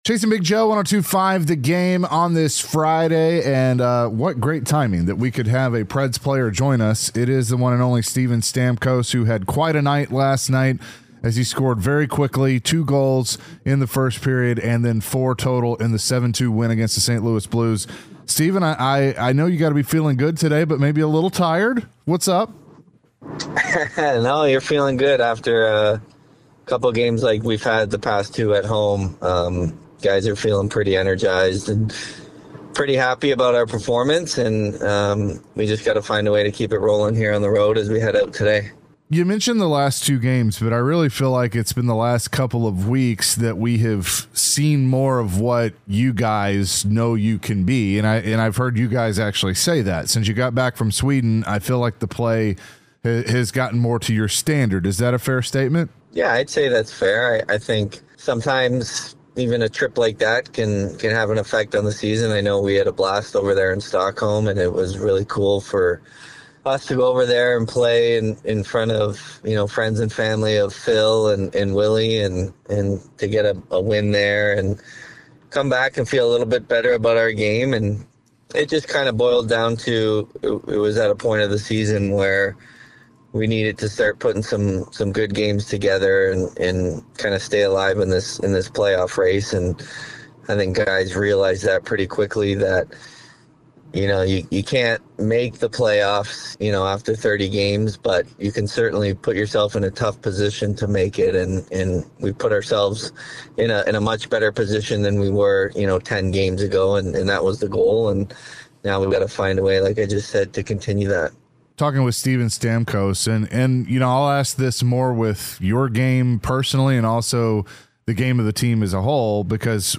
The guys speak to Steven Stamkos about his great four-goal performance, the recently improved play by the team, and how it feels during the game when he is in the zone.